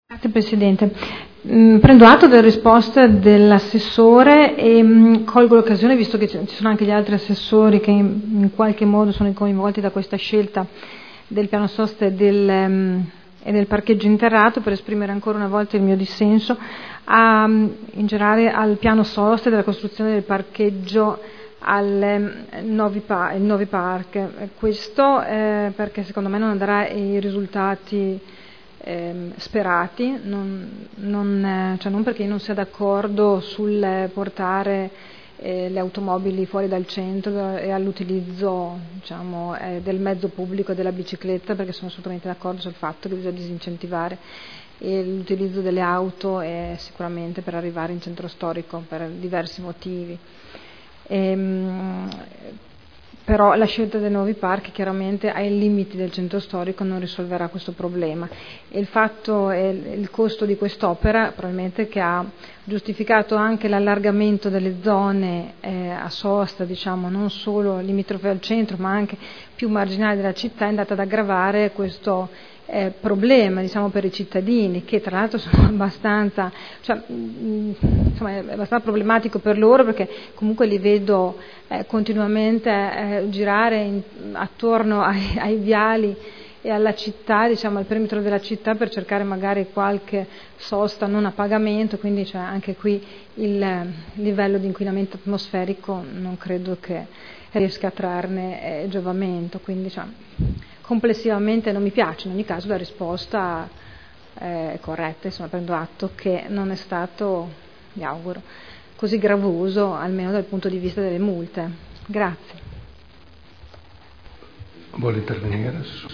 Seduta del 15/10/2012 Dibattito.